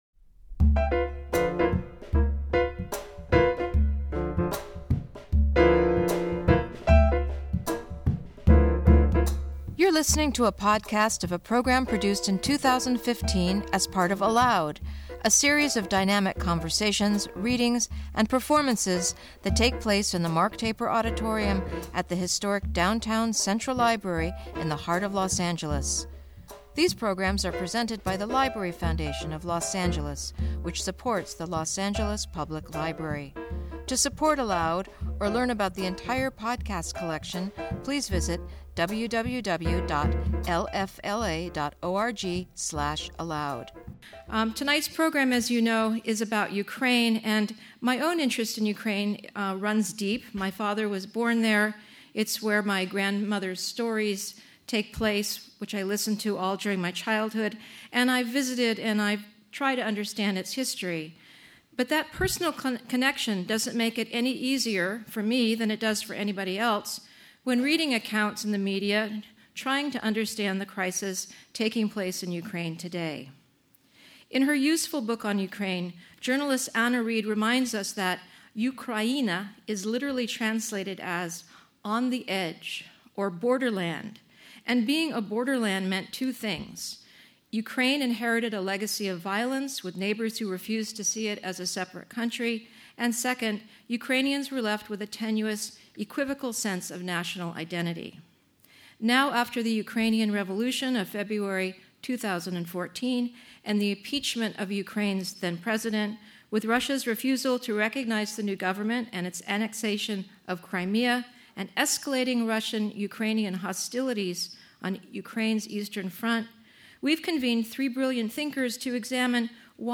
Timothy D. Snyder and Masha Gessen In conversation